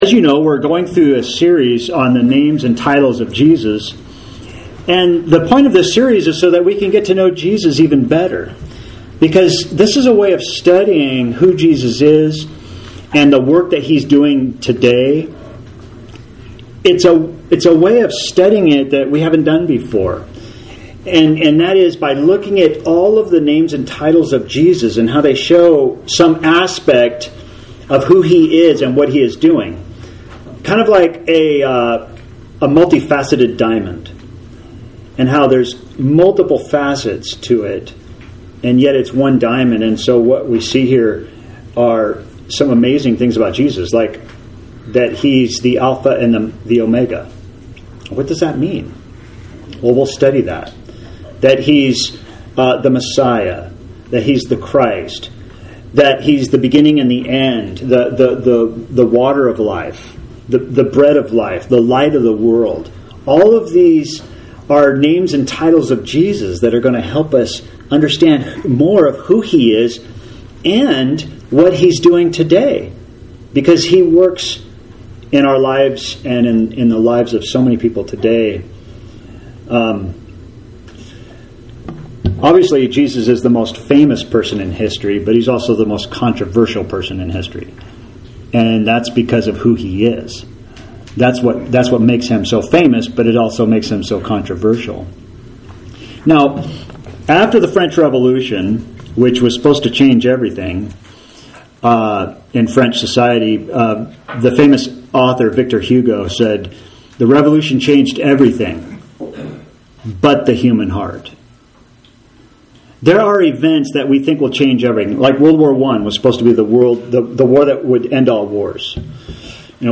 This is lesson 4 in a series JESUS: The Name Above Every Name studying the names and titles of Jesus in order to know Him better.